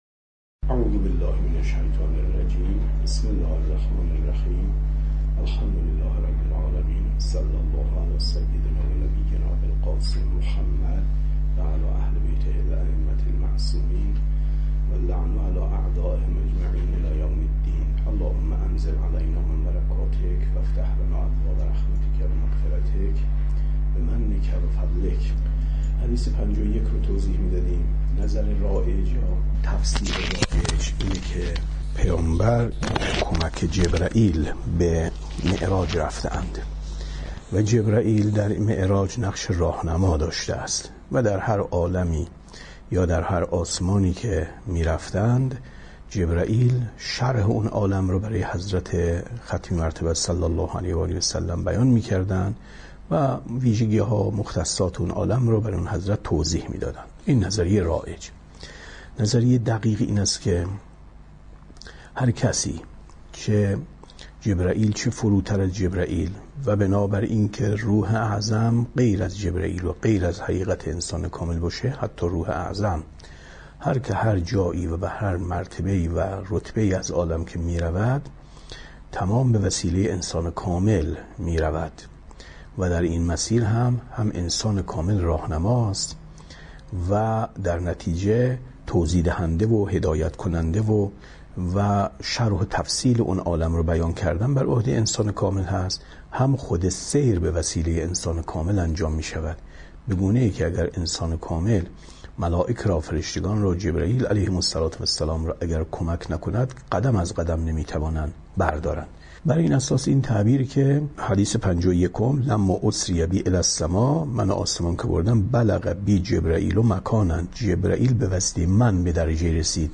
کتاب توحید ـ درس 53 ـ 25/ 10/ 95